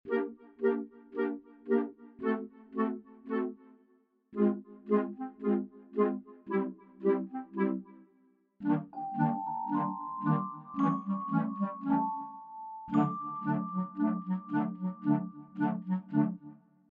Its a perfect mix of wonder and puzzlement.